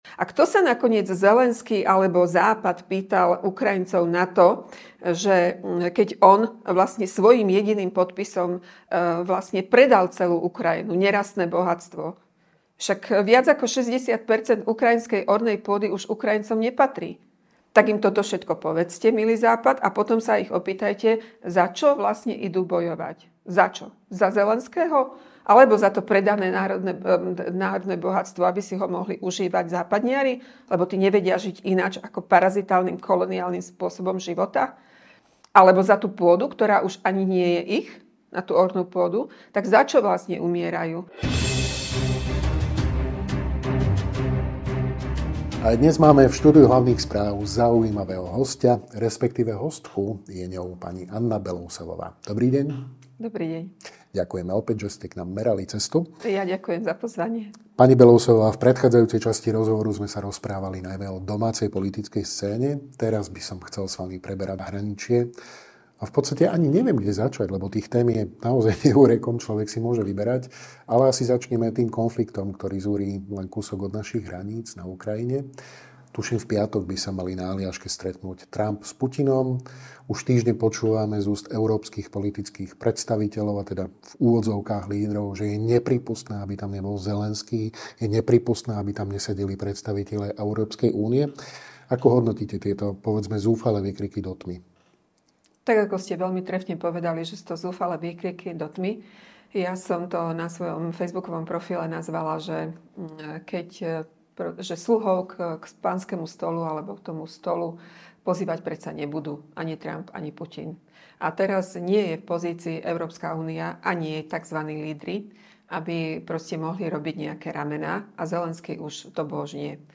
Viac sa dozviete vo videorozhovore s dlhoročnou političkou, momentálne na komunálnej úrovni, RNDr. Annou Belousovovou.
(Nahrávané pred stretnutím prezidentov Putina a Trumpa na Aljaške, 12.8.)